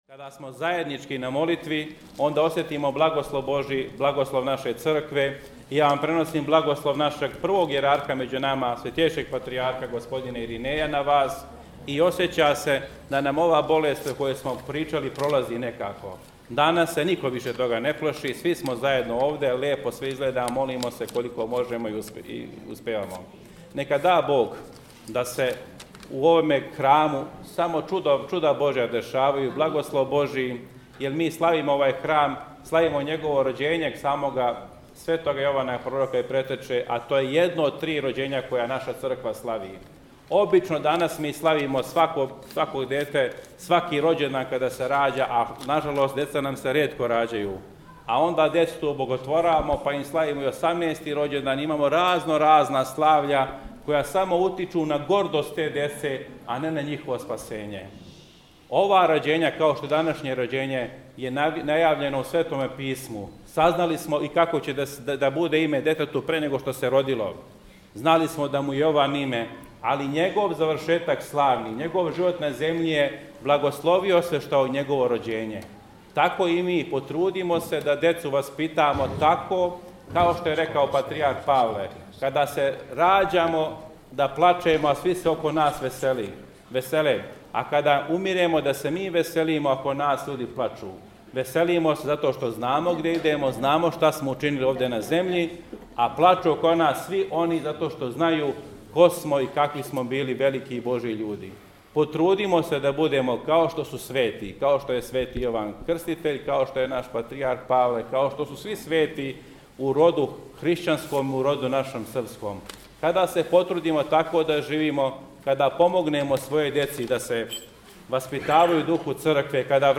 На празник Рођења Светог Јована Претече и Крститеља (Ивањдан), храм на Петловом брду посвећен овом празнику, прославио је своју славу.
Звучни запис беседе